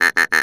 ANIMAL_Duck_07_mono.wav